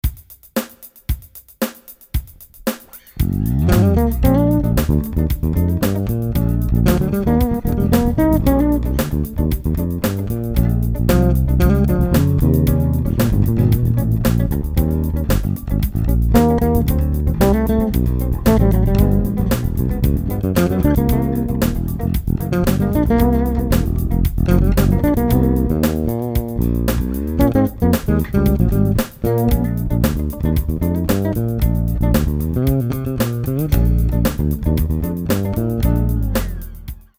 The bass looks and sounds fantastic.
He makes the bass sound very nice, eh?
• Model: Classic J - Fretless
• Neck Pickup: Humphrey J Noiseless (Black)